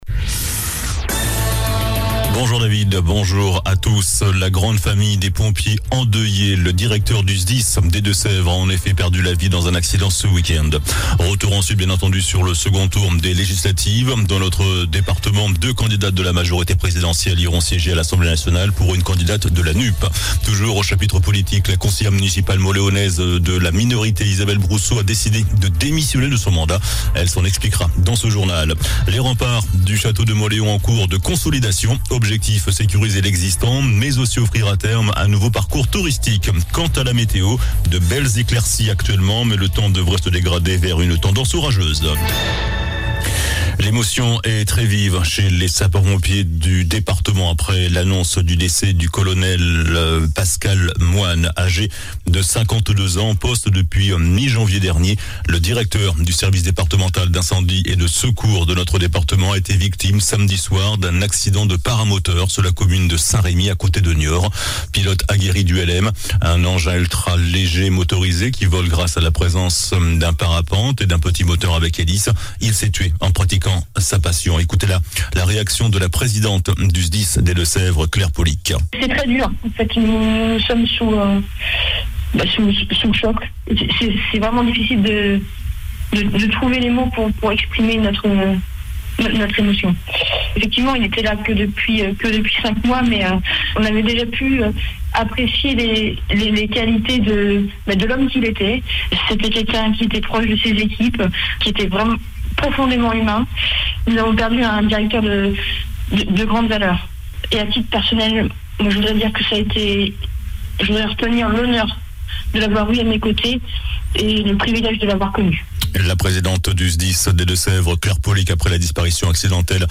JOURNAL DU LUNDI 20 JUIN ( MIDI )